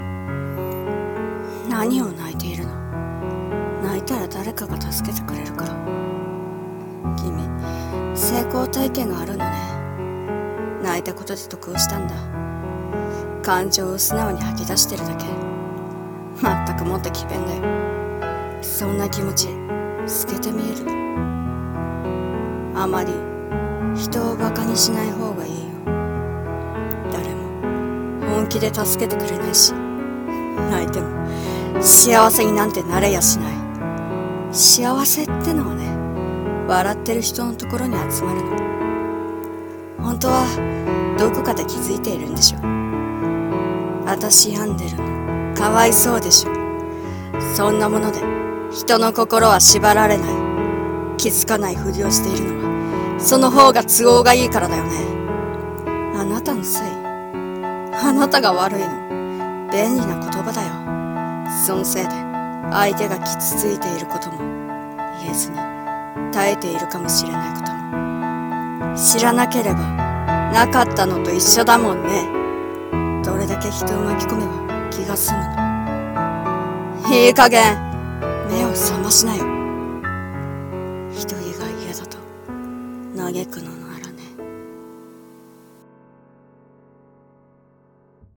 【声劇台本】独話。